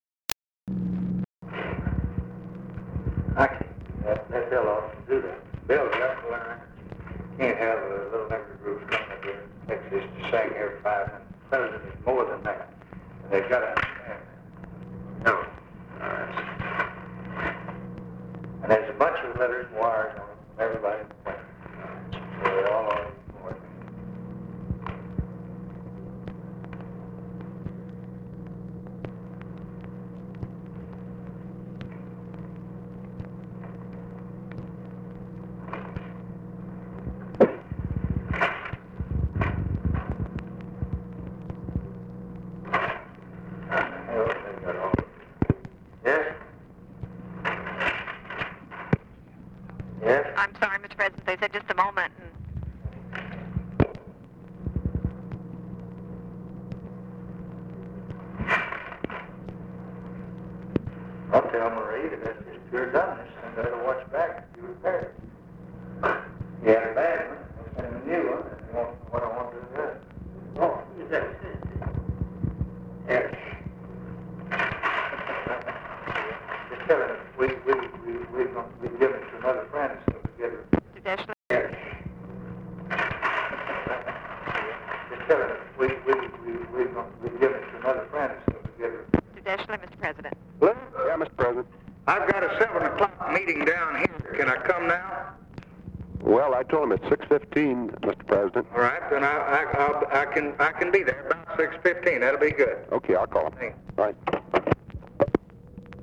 LBJ TALKS WITH SOMEONE IN HIS OFFICE
OFFICE CONVERSATION, December 10, 1963
Secret White House Tapes